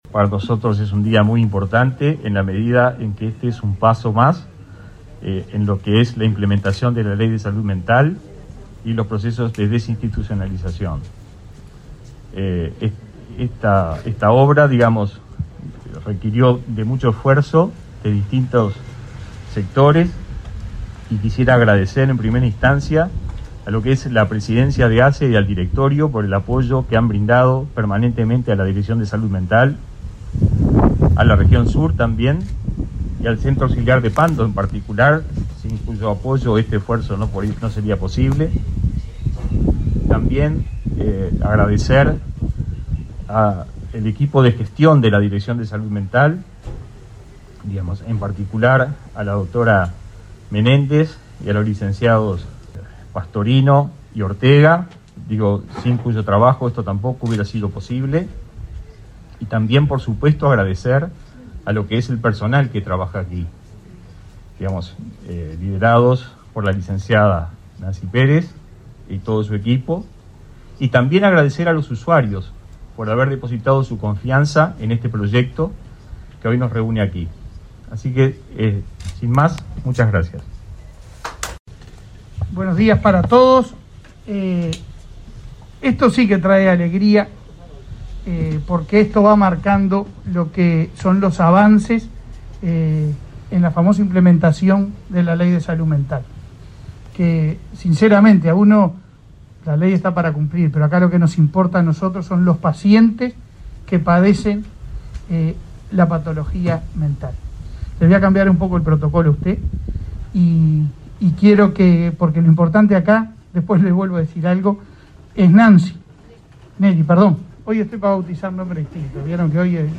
Palabras del director de Salud Mental y del presidente de ASSE